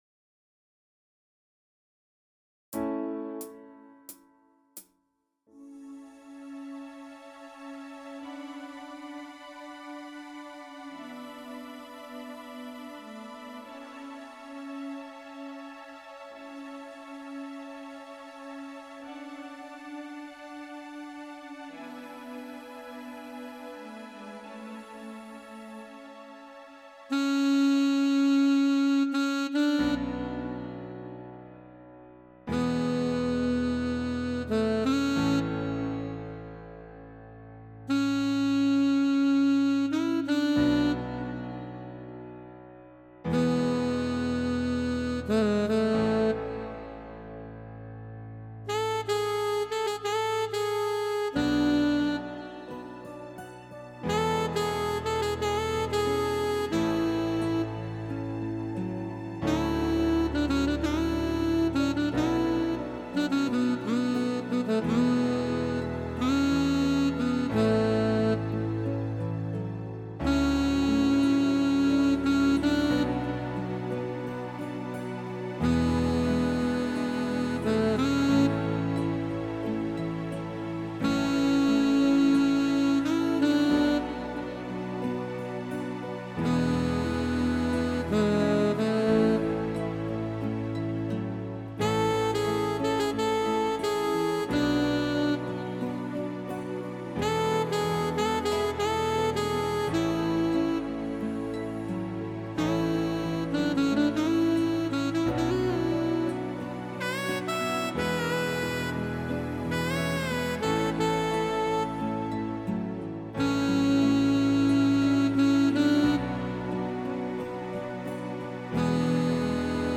MIDI Karaoke version
PRO MIDI INSTRUMENTAL VERSION